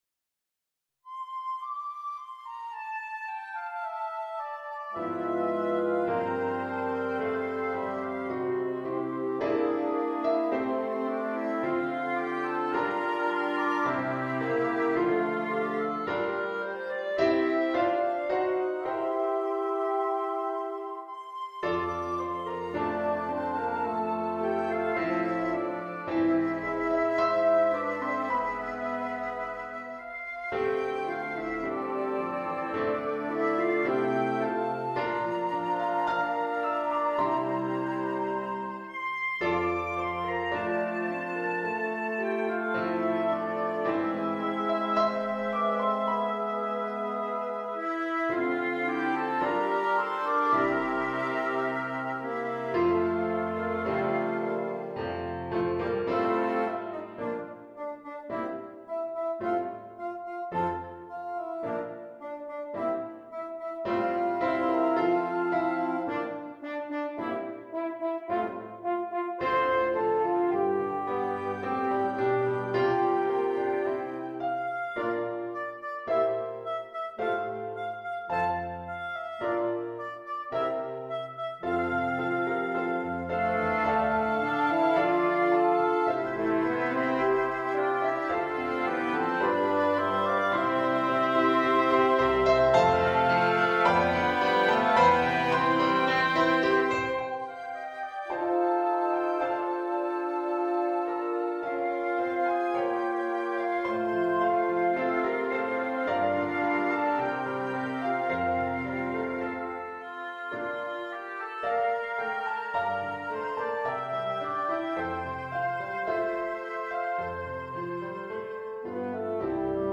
• ver. Wind Quintet & Piano
※ This sheet music is arranged for a Wind Quintet & Piano